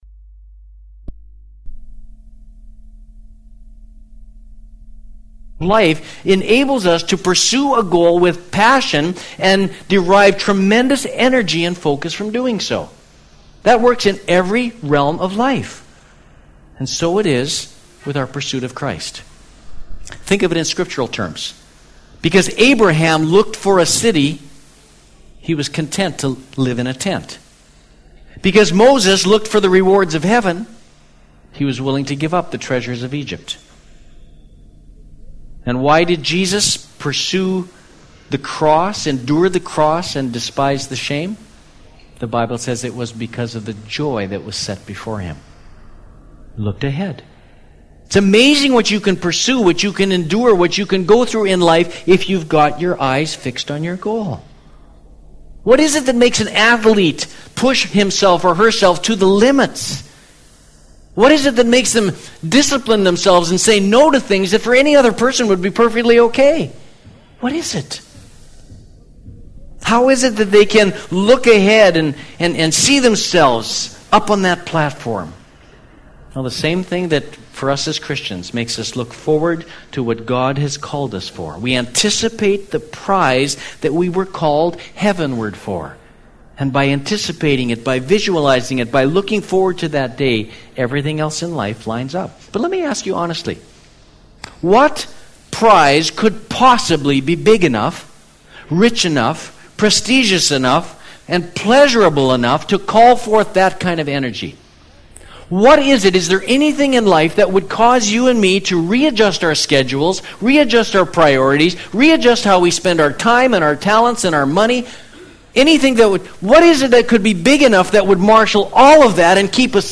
2005 Going the Distance Preacher